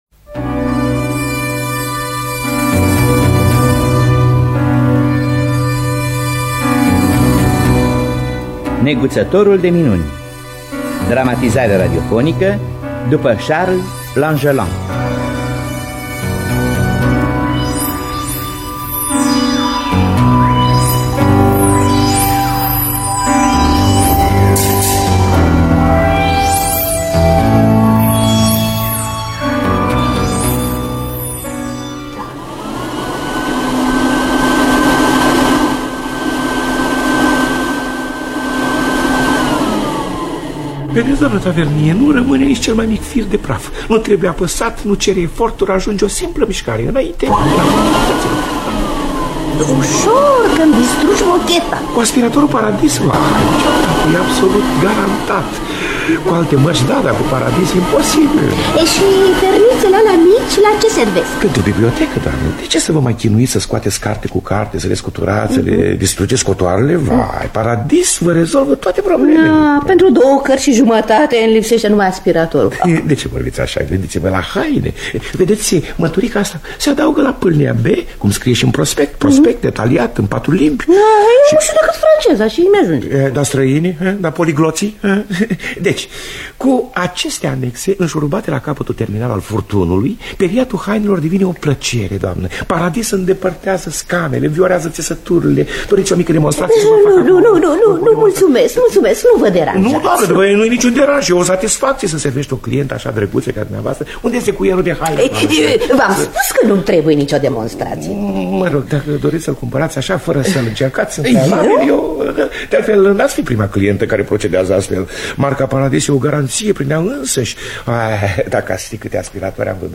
Neguțătorul de minuni de Charles Langelaan – Teatru Radiofonic Online